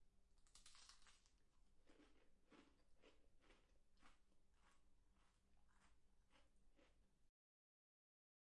僵尸的声音 " 2 僵尸吃东西
描述：使用Zoom H4Mp3 320 kbps进行录制
标签： 咀嚼 僵尸 唾液
声道立体声